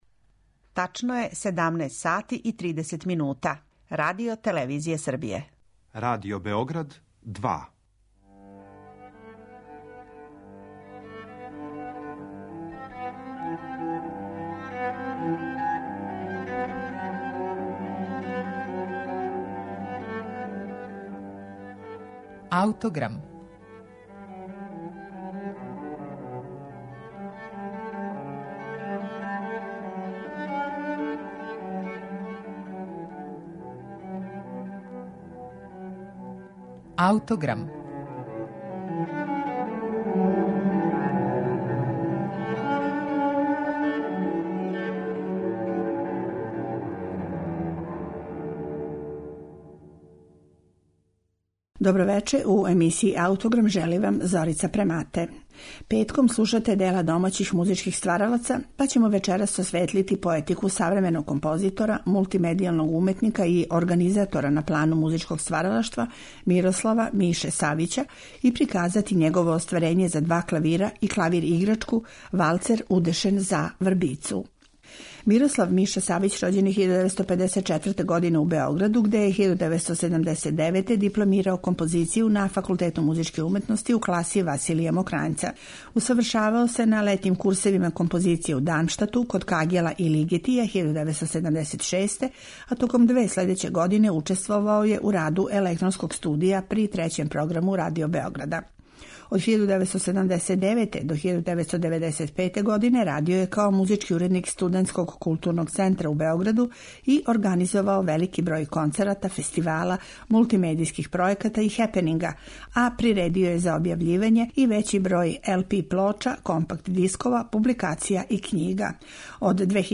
Данас ће бити речи о стилу овог нашег композитора, минималисте, концептуалисте и видео-уметника, а поменути духовити и меланхолични валцер представићемо у верзији за клавирски дуо и клавир-играчку.